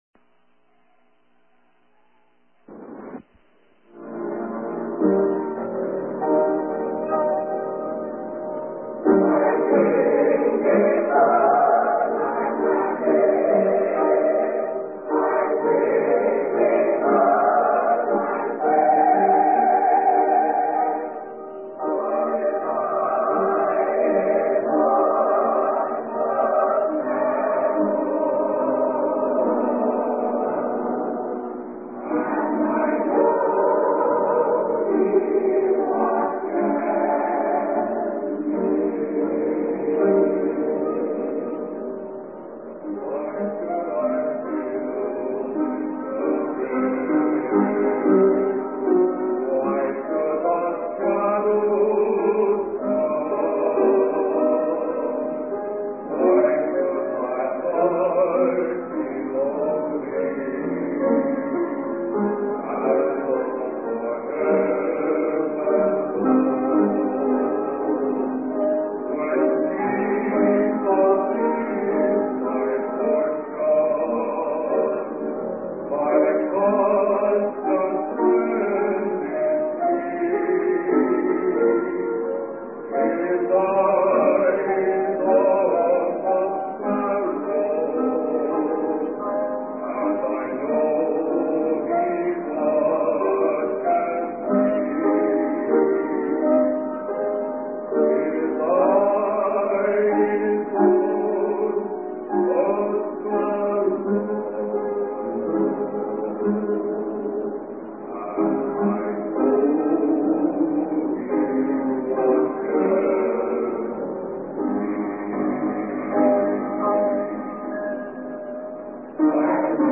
Bristol Conference 1976-17
He contrasts Jephthah's leadership with that of previous judges, noting the people's choice of a flawed leader and the consequences of their idolatry. The sermon discusses the importance of genuine repentance and the dangers of rash vows, as seen in Jephthah's tragic promise to sacrifice whatever came out of his house.